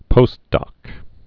(pōstdŏk) Informal